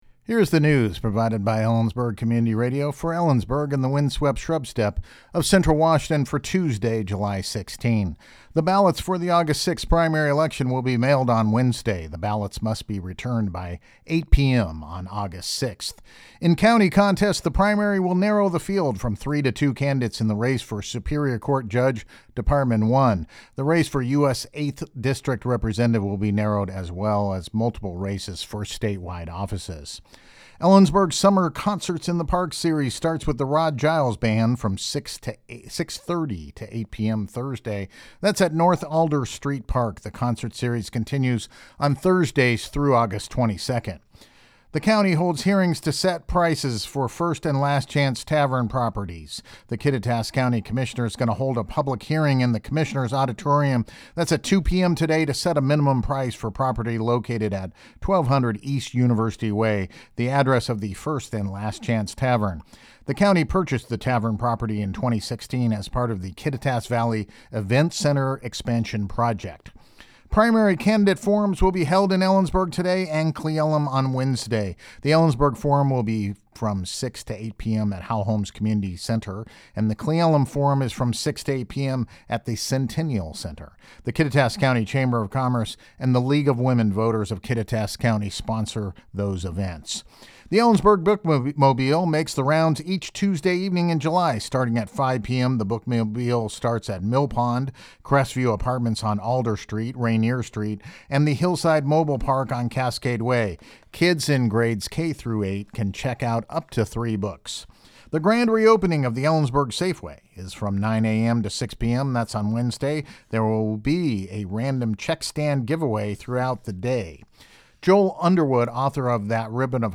LISTEN TO THE NEWS HERE NEWS Primary ballots to be mailed The ballots for the Aug. 6 primary election will be mailed on Wednesday.